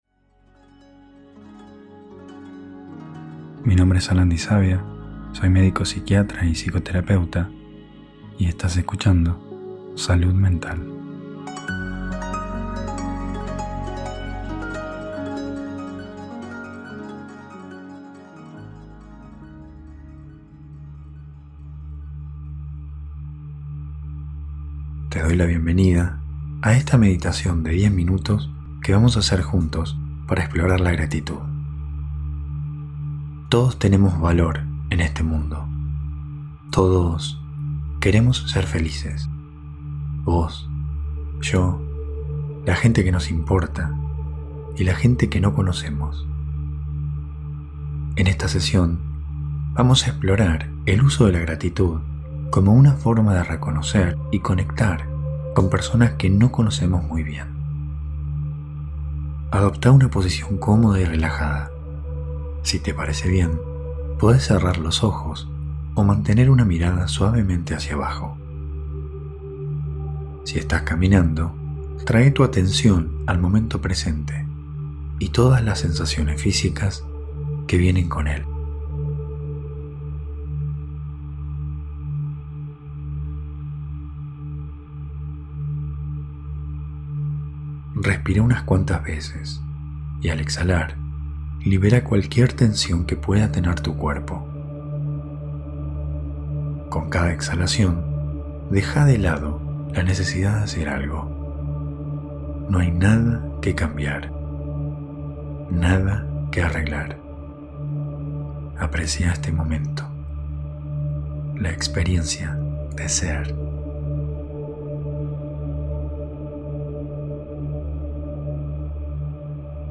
Meditemos Juntos • Gratitud • 10min